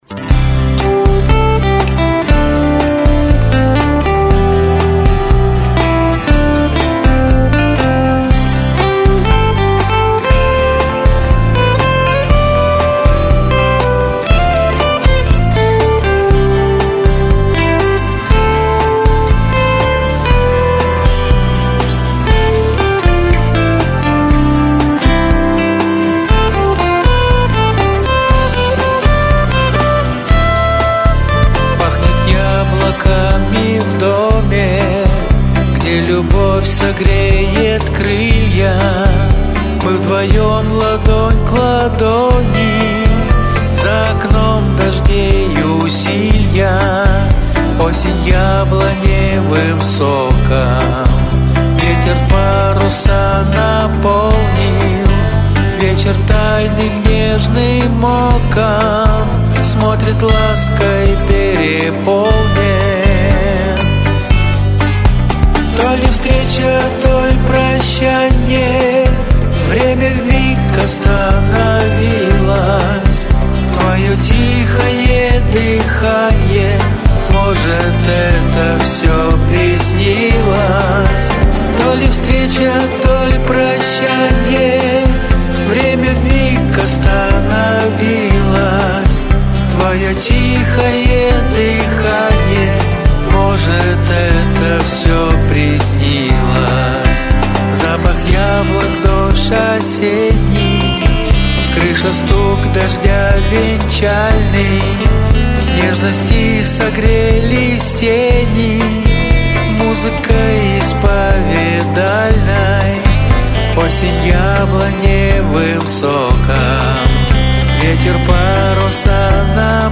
Песня нежная, лиричная.Какая- то уютная по домашнему.